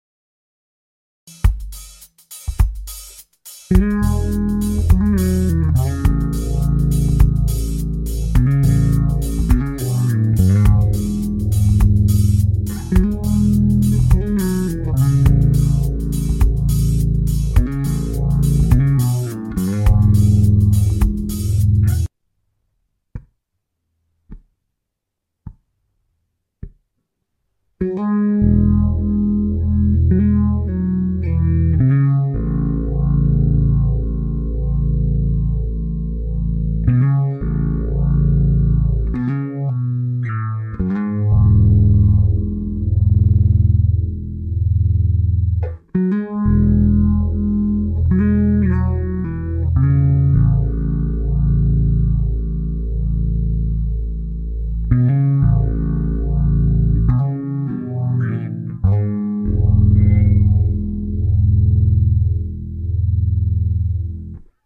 L74 Simple chording for bass EMajor to AMajor
L74-Simple-chording-E-major-to-A-major.mp3